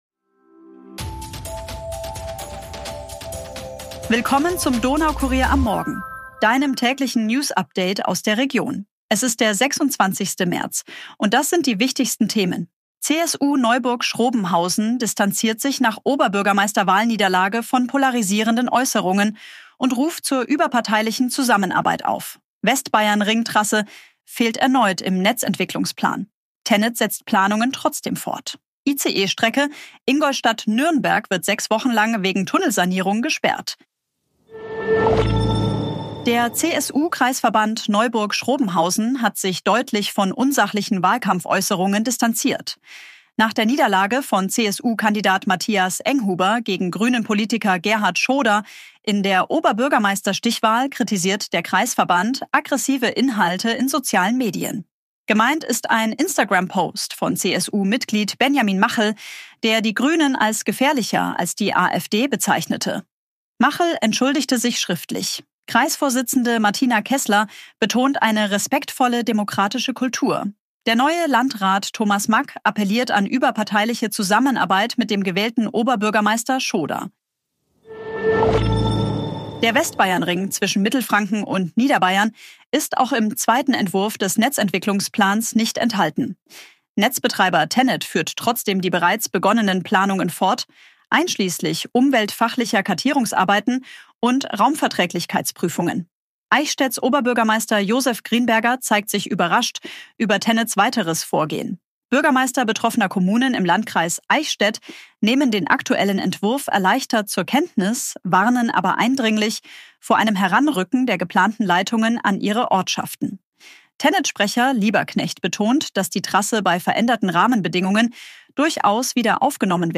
Dein tägliches News-Update